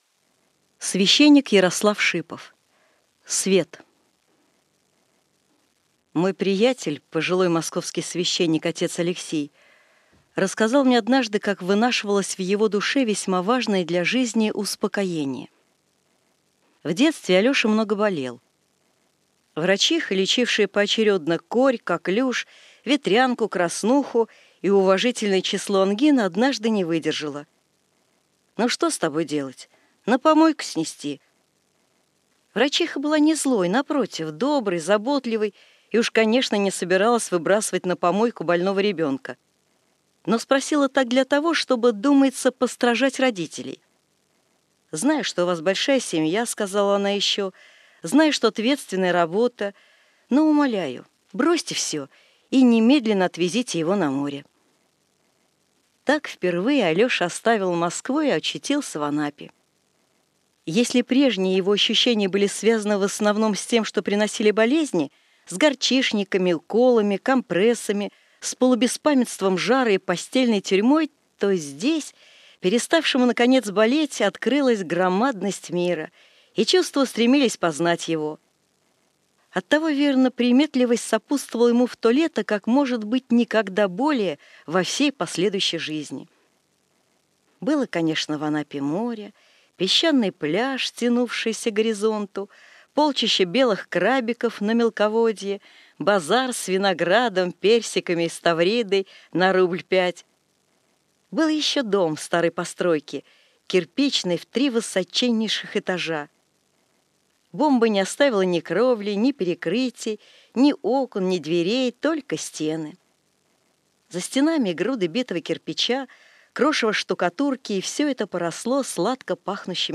Литературные чтения